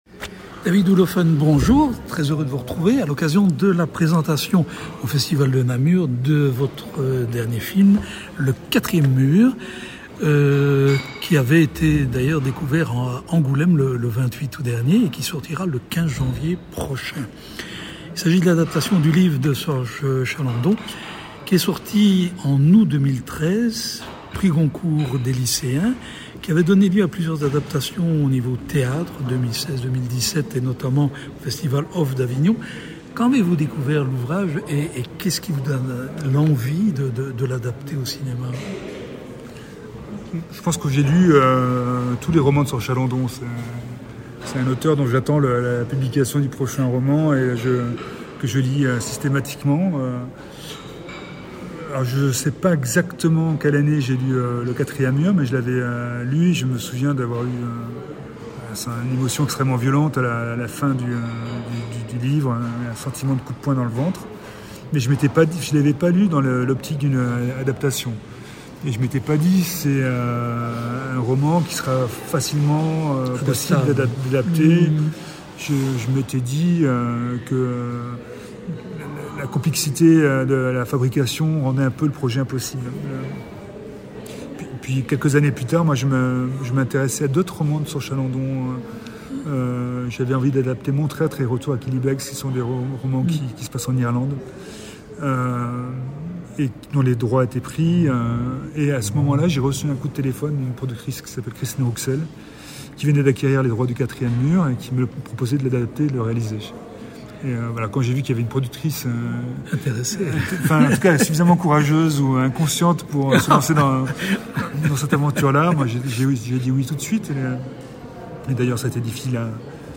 Rencontre avec le metteur en scène. On y parle de Prix Goncourt, de vanité, de Candide, de Simon Abkarian, d’utopie, de Comédie Française, de caméscope, d’Avignon…